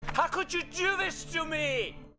Play, download and share How could you original sound button!!!!
notification_howcouldyoudothistome.mp3